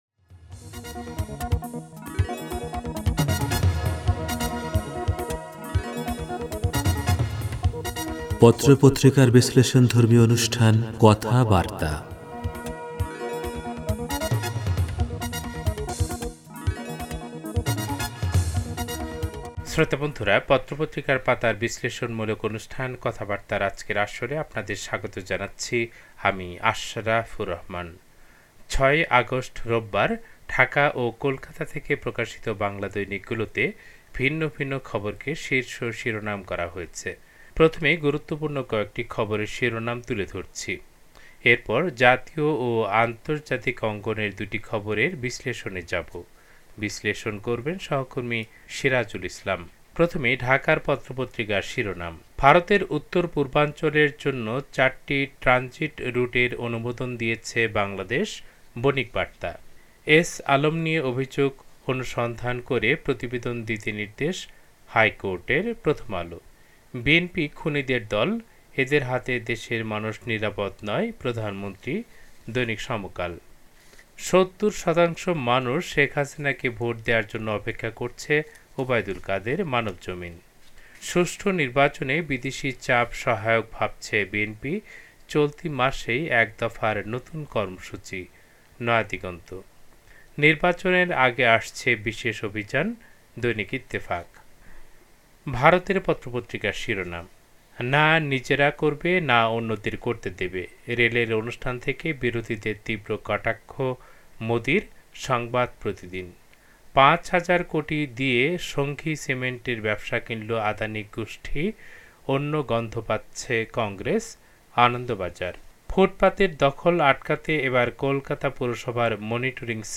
পত্রপত্রিকার পাতার অনুষ্ঠান